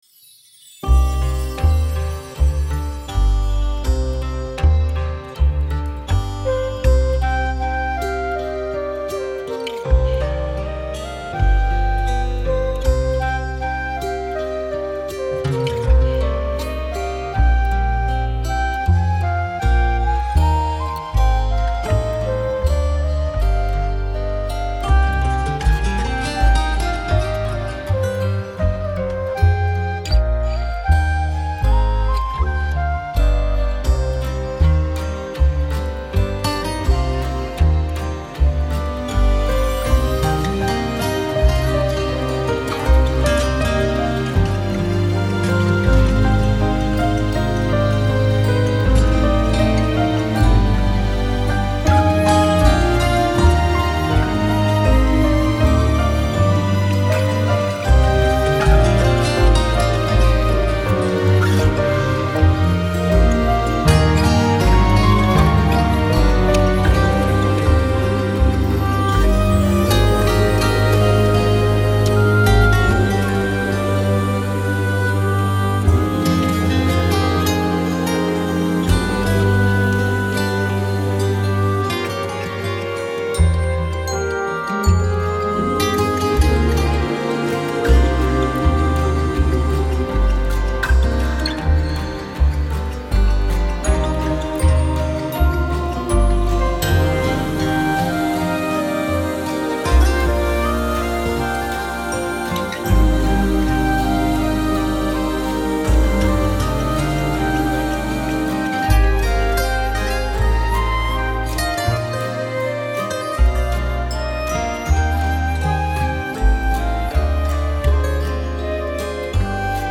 Genre: Pop-Folk.